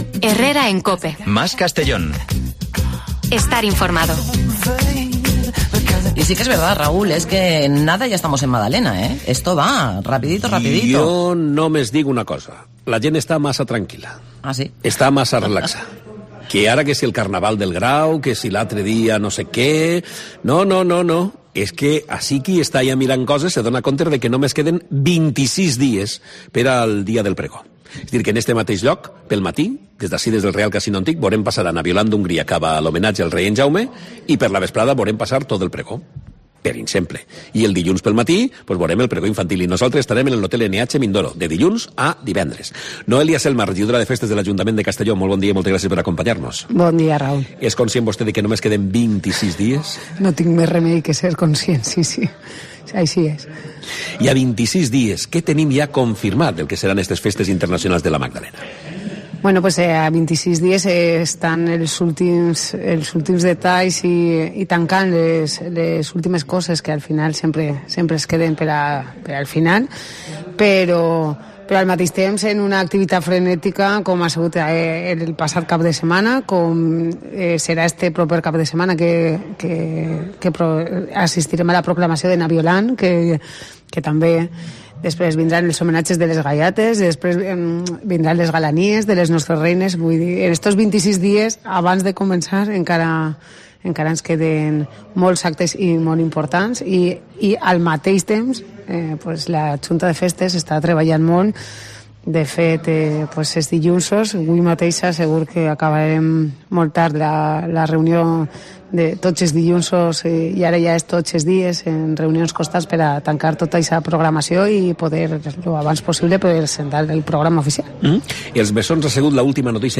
La concejala de Fiestas, Noelia Selma, explica en COPE los principales cambios que se van a producir en las Fiestas de la Magdalena